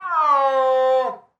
Звуки снежного барса
Мяуканье снежного барса